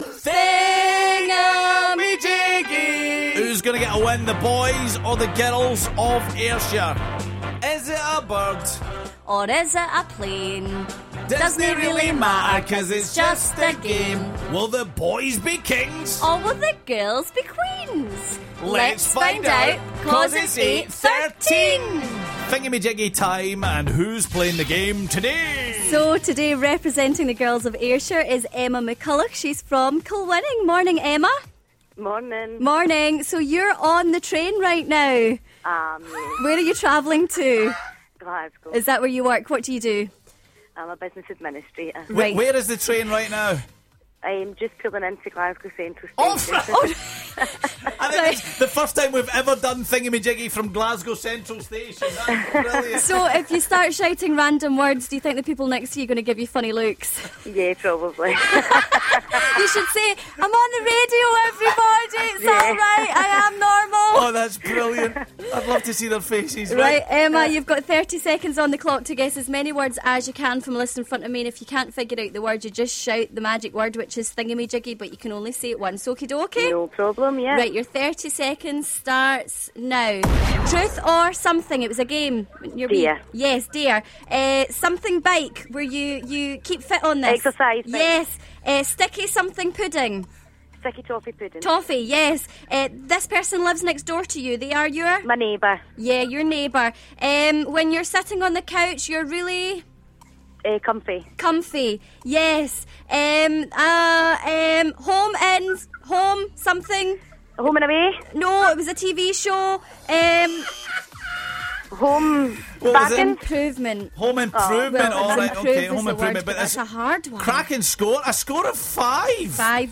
Its the first time Thingummyjiggy has ever been played live from the train at Glasgow Central Station.